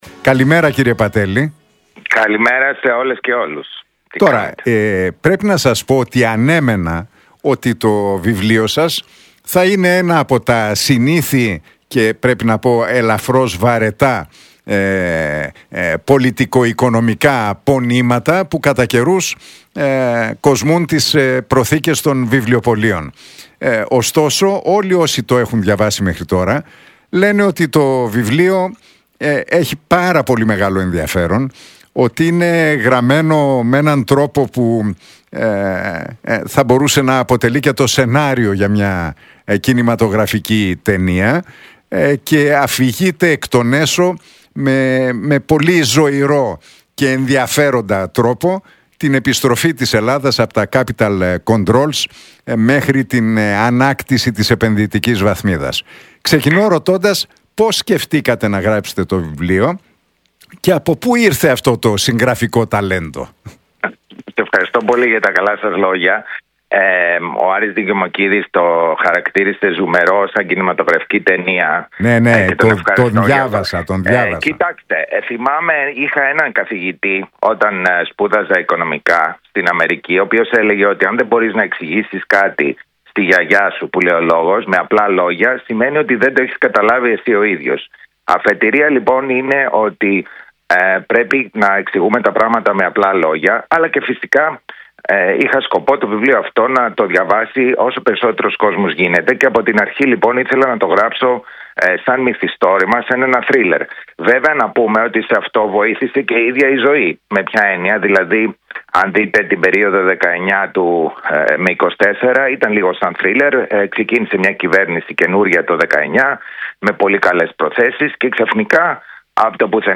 Ο Αλέξης Πατέλης στον Realfm 97,8 για το βιβλίο του «Η Μεγάλη Επιστροφή: Ο δρόμος για την αξιοπιστία της Ελλάδας»: Ήθελα να απευθύνεται σε όλους
«Είχα έναν καθηγητή όταν σπούδαζα στην Αμερική που έλεγε ότι αν δεν μπορείς να εξηγήσεις κάτι με απλά λόγια στην γιαγιά σου, δεν το έχεις καταλάβει και εσύ. Ήθελα να γράψω το βιβλίο σαν μυθιστόρημα, σαν θρίλερ, αλλά βοήθησε και η ίδια η ζωή σε αυτό» δήλωσε ο πρώην Προϊστάμενος του Οικονομικού Γραφείου του Πρωθυπουργού, Αλέξης Πατέλης, μιλώντας στην εκπομπή του Νίκου Χατζηνικολάου στον Realfm 97,8 για το νέο βιβλίο του «Η Μεγάλη Επιστροφή: Ο δρόμος για την αξιοπιστία της Ελλάδας».